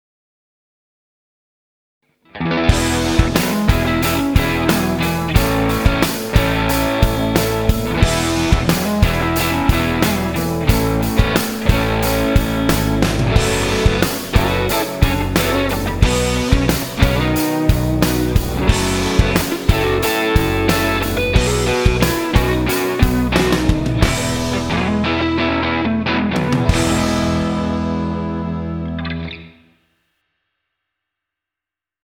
Rhythm Guitar 1
JIMI HENDRIX style
Jimi Hendrix style.mp3